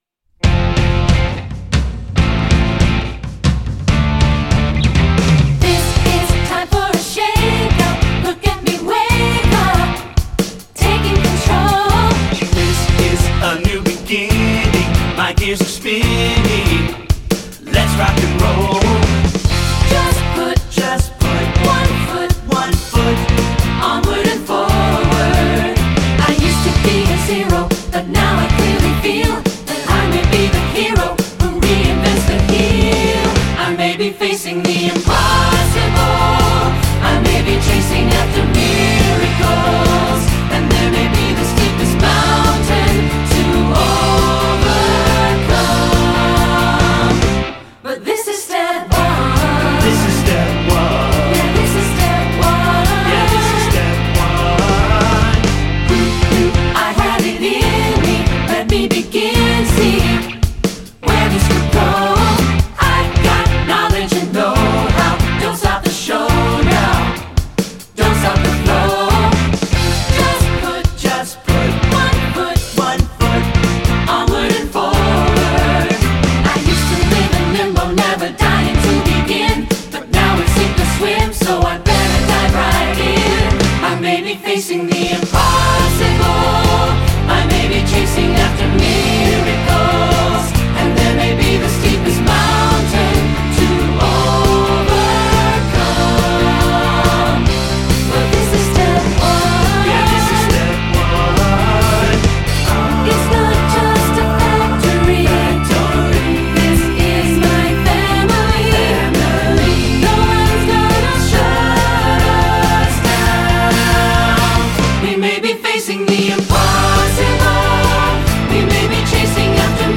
choral
SATB